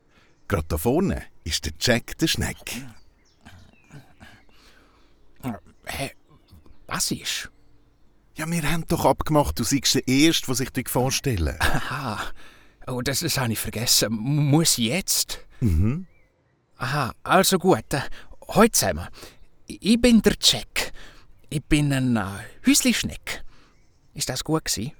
Abentüür uf de Wiese Folge 1-4 ★ Aktion ★ CH Dialekt Hörspiel
Schwiizer Dialekt Gschichte